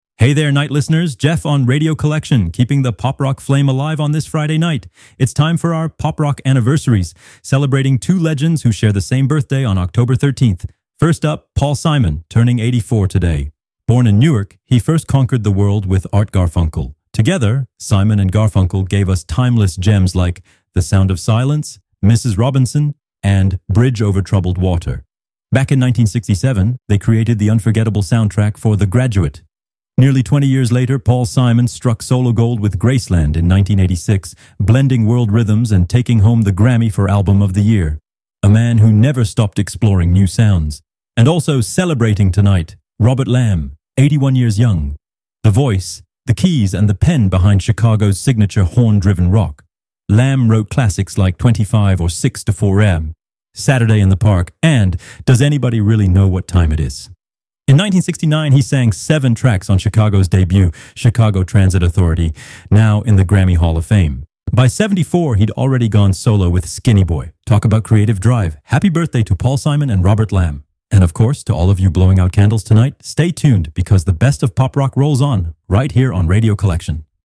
You're listening to the Pop Rock column on Radio Collection, the free, ad-free web radio station that broadcasts the greatest classics and new releases in Hi-Fi quality.